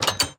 Minecraft Version Minecraft Version latest Latest Release | Latest Snapshot latest / assets / minecraft / sounds / block / iron_door / open4.ogg Compare With Compare With Latest Release | Latest Snapshot